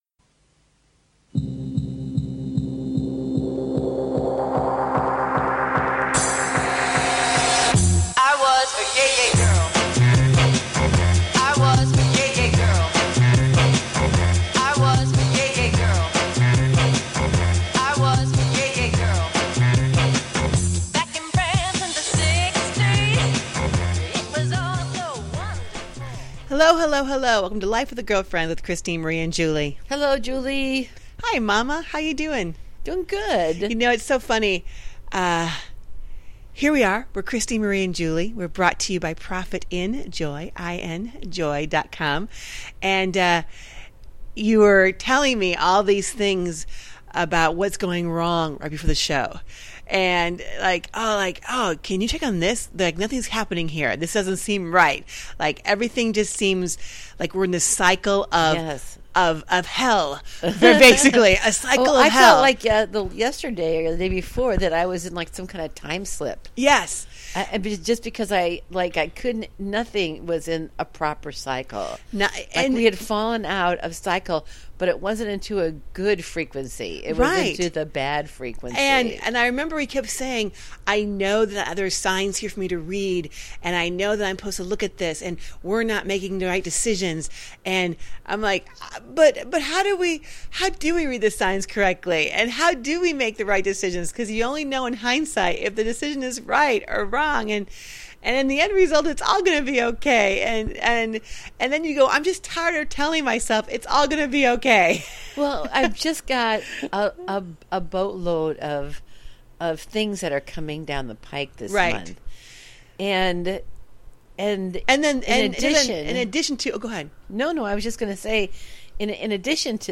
Talk Show Episode, Audio Podcast
This mother/daughter coaching duo shares their everyday thoughts on relationships, family, hot topics and current events, and anything that tickles their fancy with warmth, wit, and wisdom.
And join the girlfriends up close and personal for some daily chat that’s humorous, wholesome, and heartfelt.